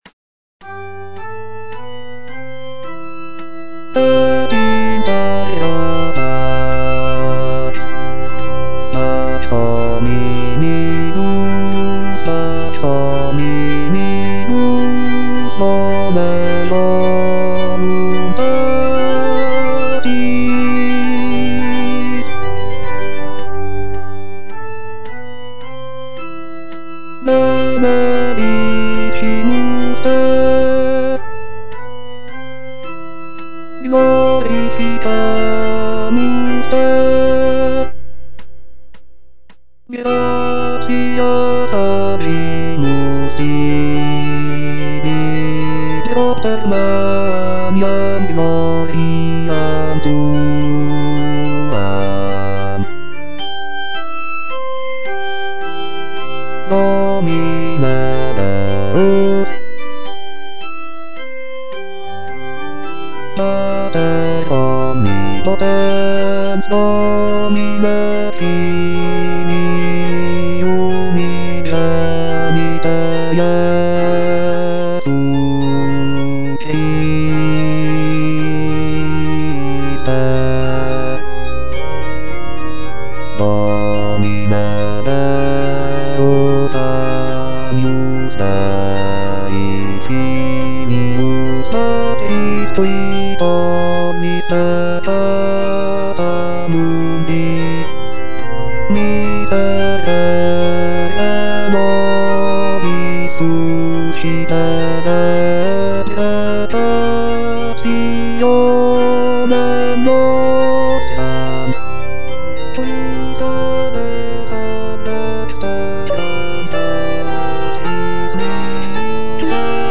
J.G. RHEINBERGER - MASS IN C OP. 169 Gloria - Bass (Voice with metronome) Ads stop: auto-stop